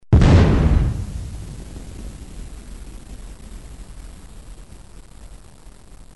دانلود آهنگ بمب و موشک 21 از افکت صوتی حمل و نقل
جلوه های صوتی
دانلود صدای بمب و موشک 21 از ساعد نیوز با لینک مستقیم و کیفیت بالا